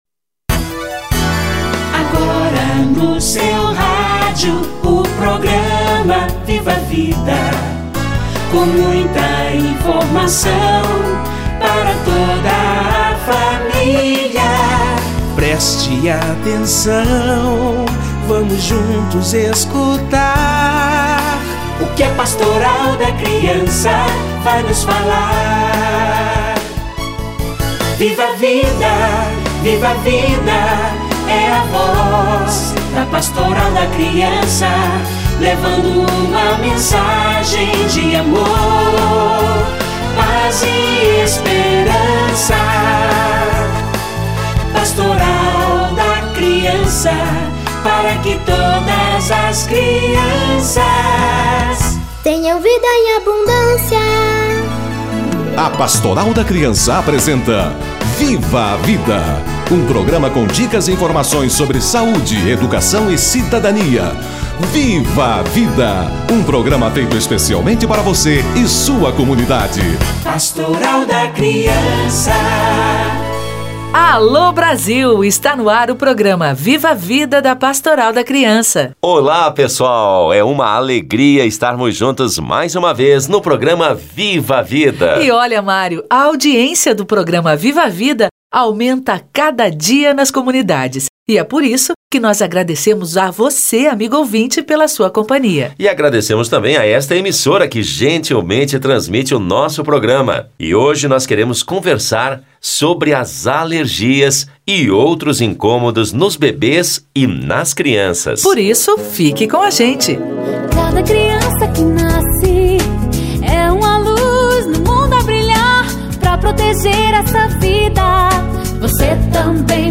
Alergias e outros incômodos da criança - Entrevista